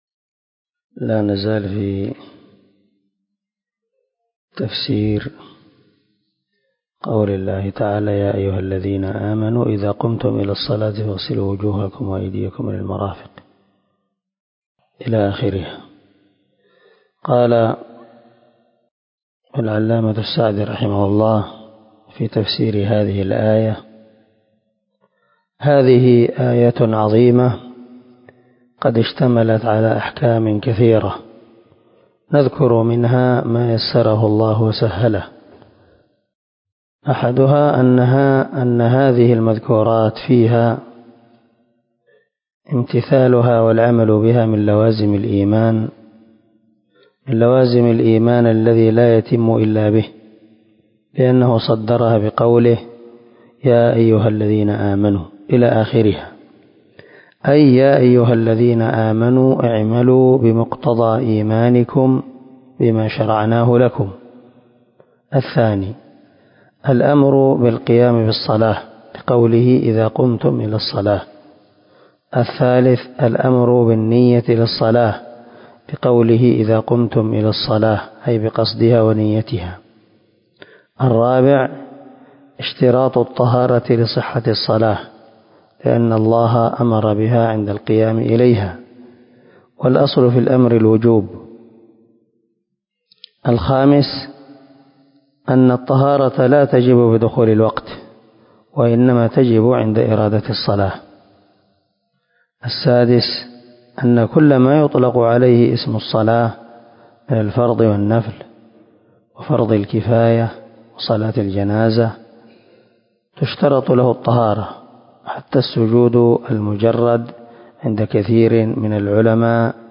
342الدرس 9 تابع تفسير آية ( 6 ) من سورة المائدة من تفسير القران الكريم مع قراءة لتفسير السعدي
دار الحديث- المَحاوِلة- الصبيحة.